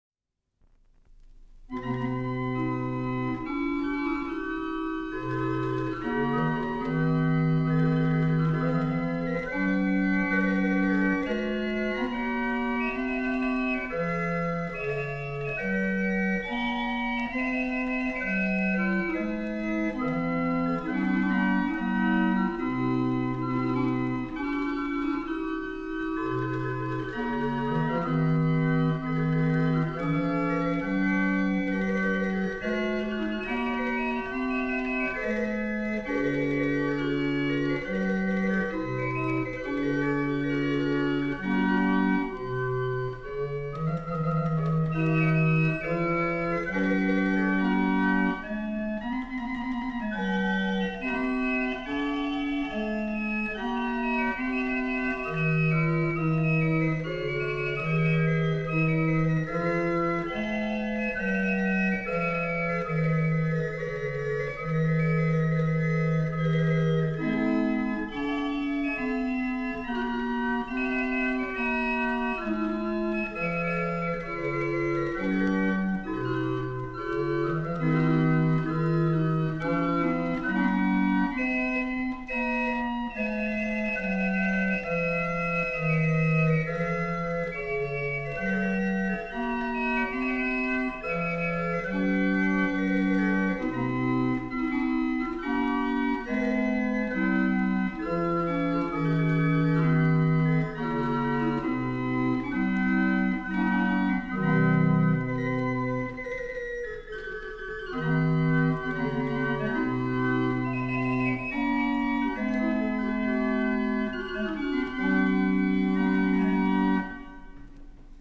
Also, I've been looking at a performance of Haendel, as preserved in a historic barrel organ: